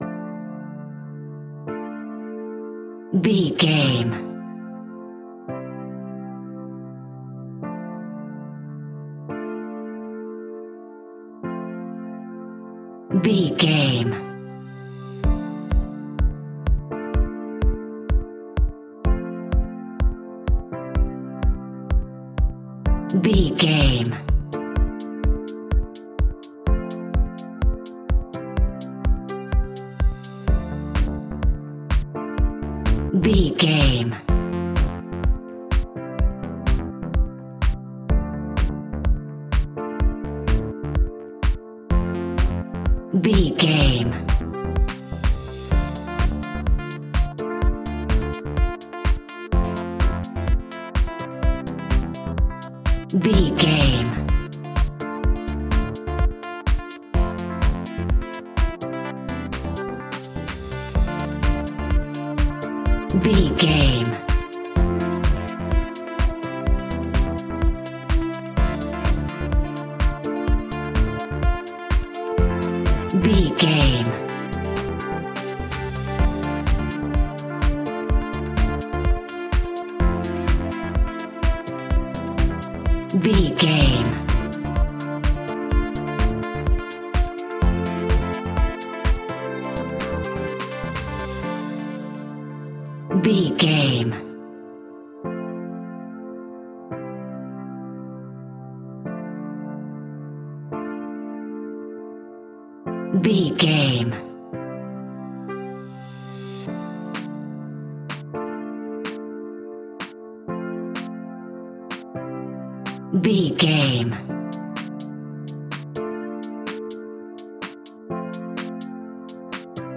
Aeolian/Minor
D
groovy
energetic
uplifting
hypnotic
drum machine
synthesiser
strings
funky house
deep house
nu disco
upbeat